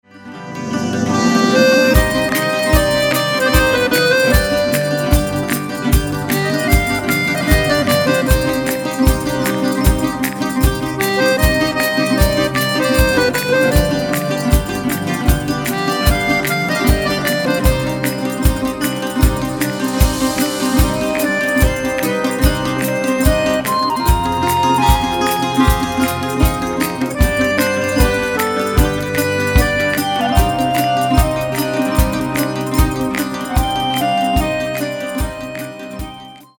Ακορντεόν
Μαντολίνο
Όργανο